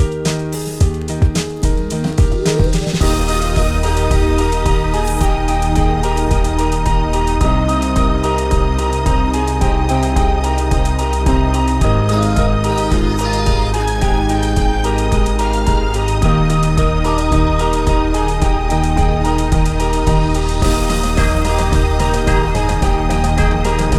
Two Semitones Down Pop (2010s) 3:36 Buy £1.50